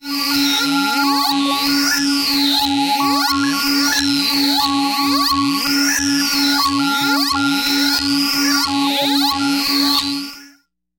描述：通过Modular Sample从模拟合成器采样的单音。
标签： CSharp5 MIDI音符-73 Korg的-Z1 合成器 单票据 多重采样
声道立体声